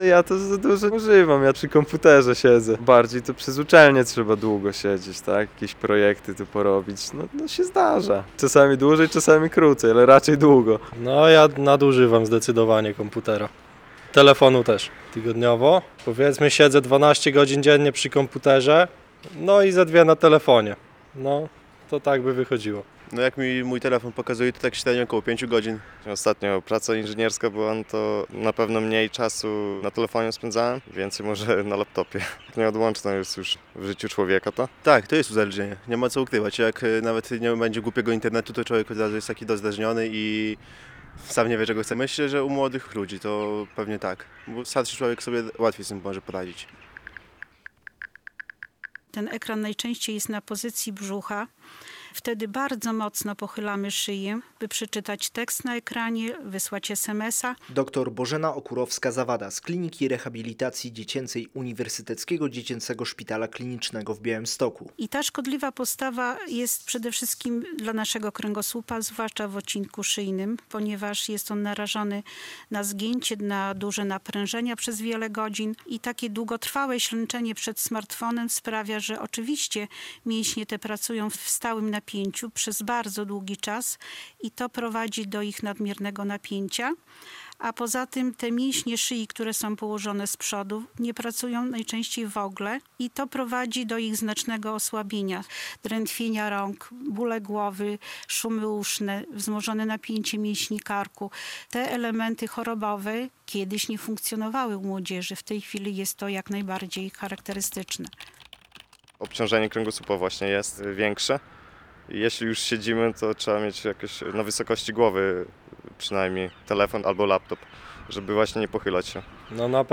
Jak mówią zapytani przez nas białostoczanie, nadużywanie sprzętu to w dzisiejszych czasach duży problem.